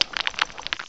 cry_not_dwebble.aif